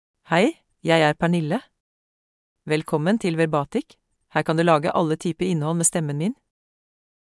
Pernille — Female Norwegian Bokmål AI voice
Voice sample
Female
Pernille delivers clear pronunciation with authentic Norway Norwegian Bokmål intonation, making your content sound professionally produced.